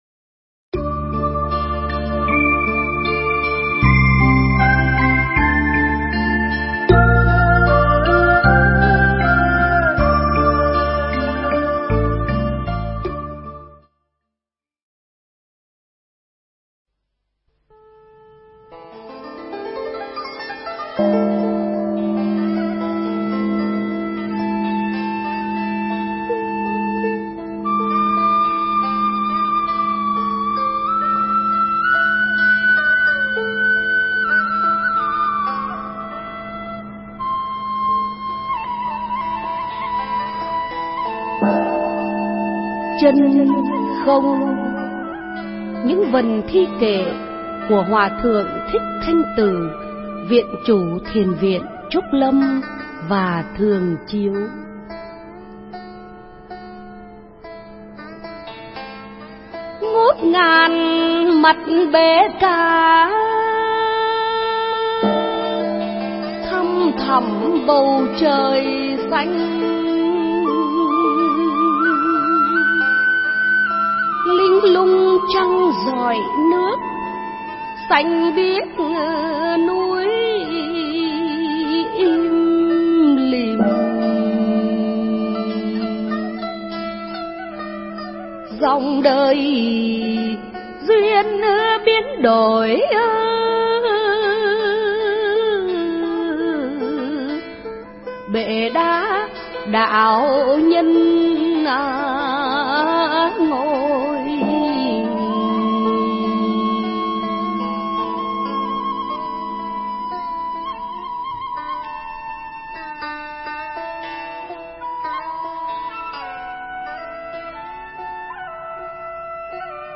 Buổi Nói Chuyện Tại Thính Pháp Đường TV Trúc Lâm Đà Lạt 1
Mp3 Pháp Thoại Buổi Nói Chuyện Tại Thính Pháp Đường TV Trúc Lâm Đà Lạt 1 – Hòa Thượng Thích Thanh Từ ngày 20 tháng 12 năm 2006 (ngày 1 tháng 11 năm Bính Tuất)